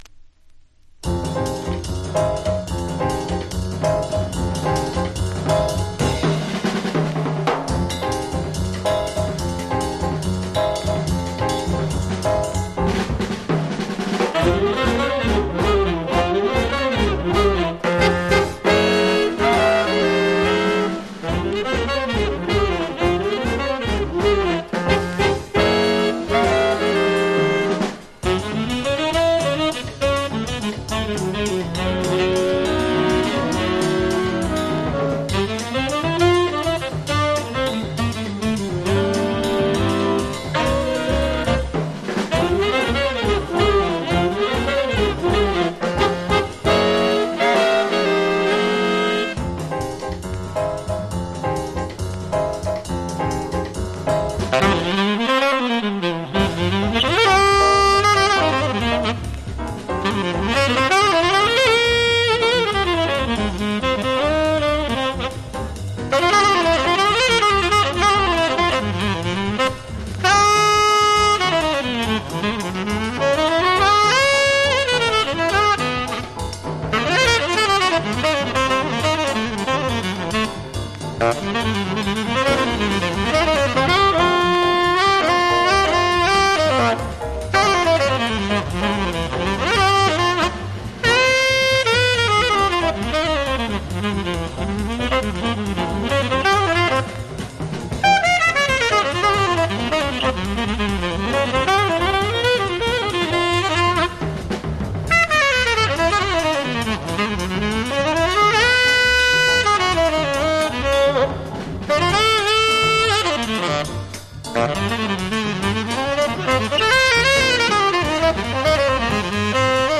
（プレス・小傷によりチリ、プチ音ある曲あり）
MONO
Genre EURO JAZZ